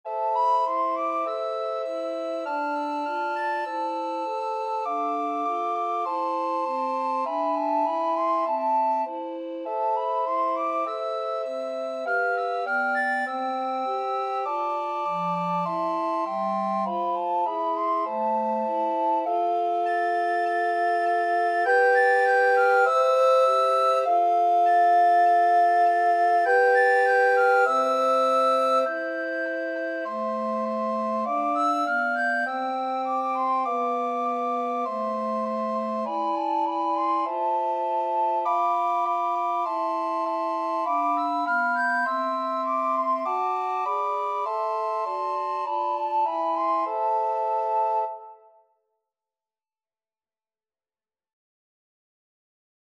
Soprano RecorderAlto RecorderTenor RecorderBass Recorder
4/4 (View more 4/4 Music)
Espressivo